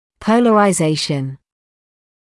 [ˌpəuləraɪ’zeɪʃn][ˌпоулэрай’зэйшн]поляризация